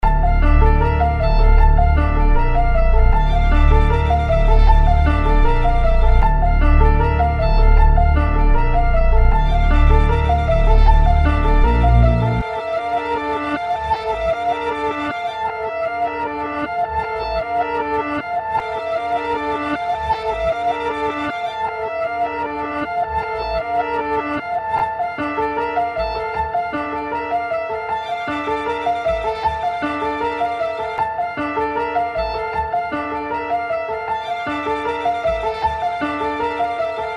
BPM 155